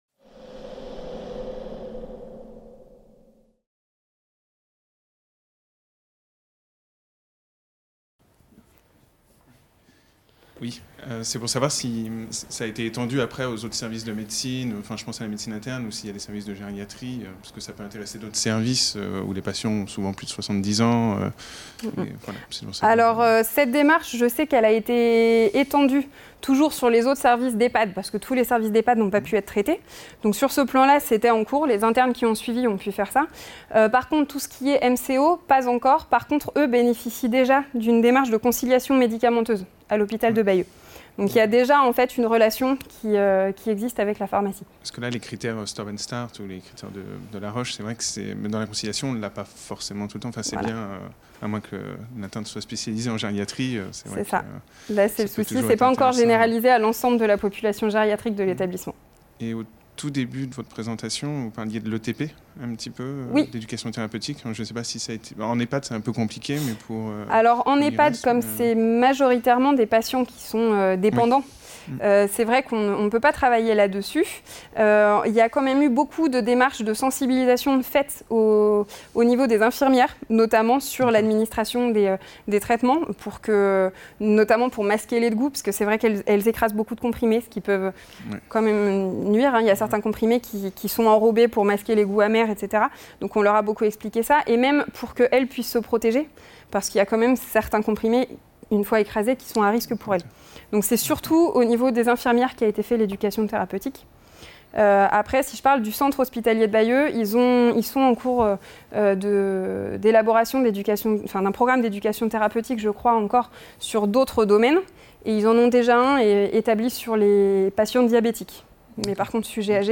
2B - Premiers pas vers la pharmacie clinique en gériatrie, échanges public (tep2018) | Canal U
Les conférences santé de la BU